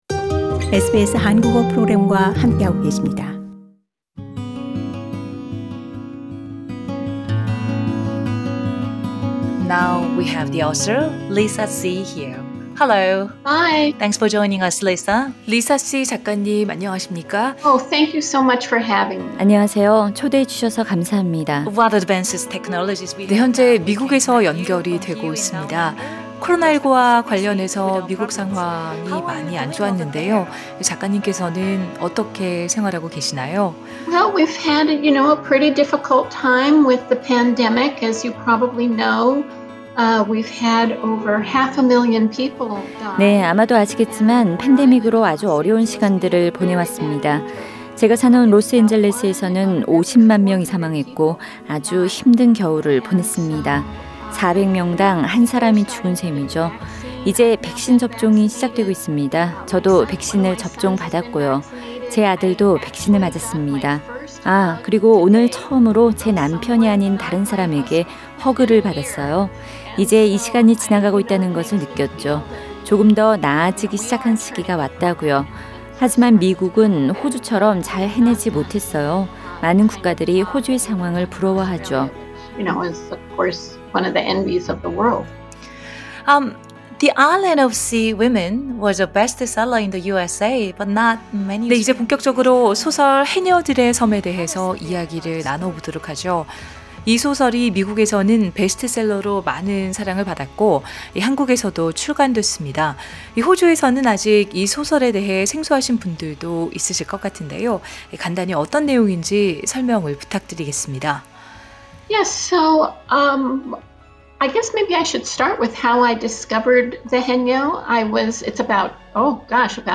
[특별 대담] ‘해녀들의 섬’ 작가 리사 시(Lisa See)의 눈에 투영된 제주 해녀들의 일평생